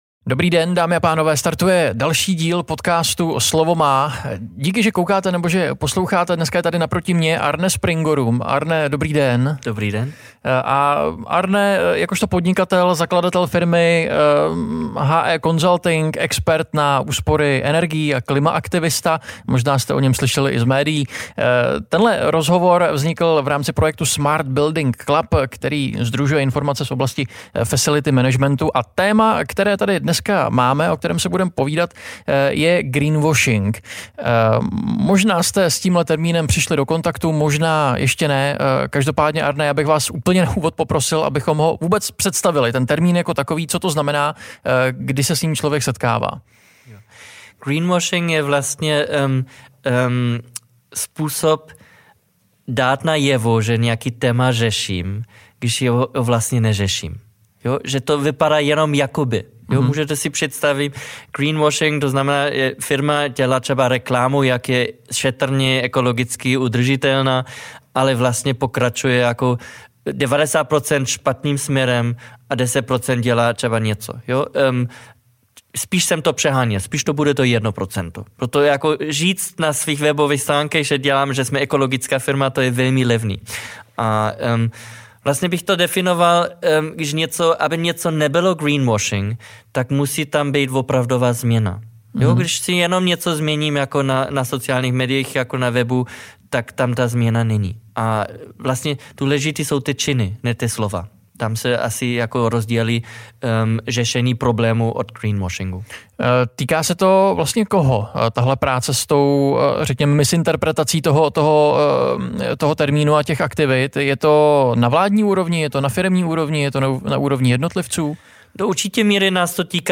Rozhovor vznikl v rámci projektu Smart Building Club, který sdružuje informace z oblasti facility managementu.